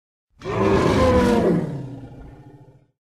Звуки мамонта
Грозный рев мамонта в ярости